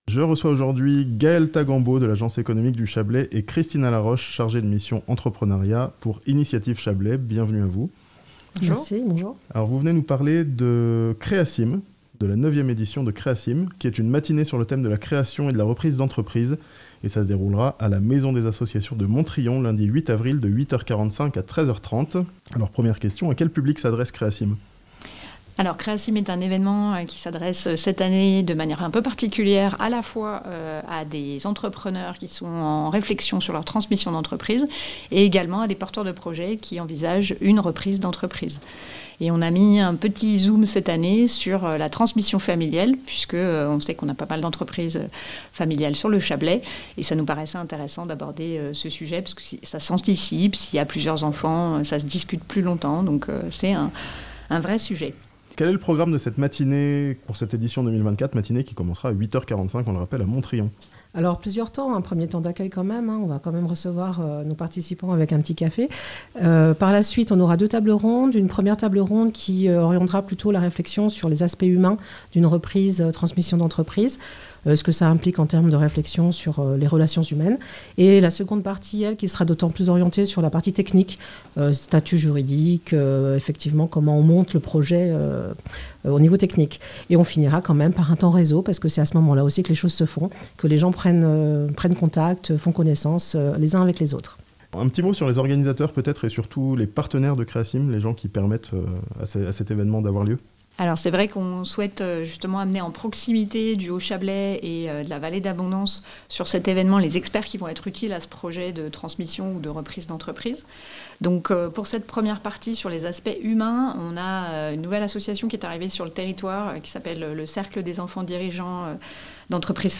Une matinée d'information sur la reprise d'entreprise le 8 avril à Montriond (interviews)